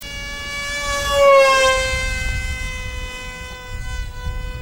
Mit diesem Motor macht das Fluggerät ein aufsehenerregendes, fast impeller- ähnliches
MP3-Hörprobe: Vorbeiflug
MP3-File Vorbeiflug (die Doppleranalyse hat ca. 70km/h ergeben)